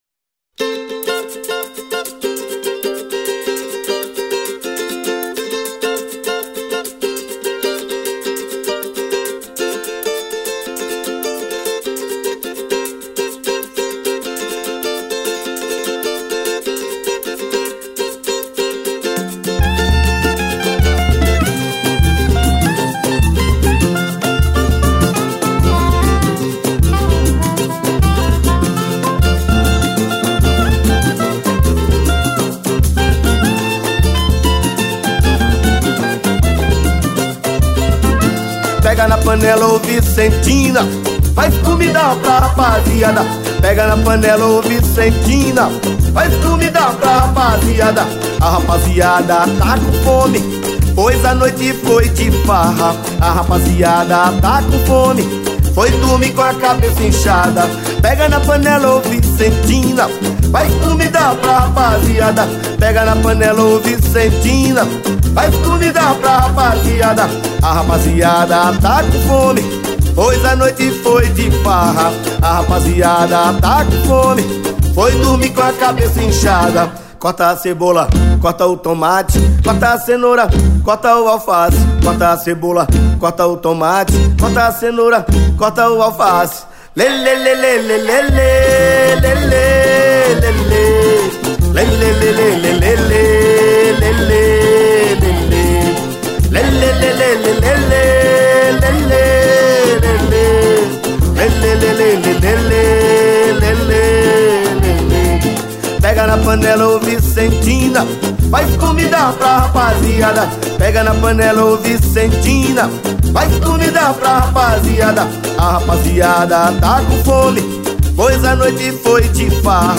86   03:12:00   Faixa:     Mpb
Cavaquinho, Sax Soprano
Vocal
Percussão
Baixo Elétrico 6, Violao Acústico 6, Voz
Flauta, Sax Soprano, Sax Alto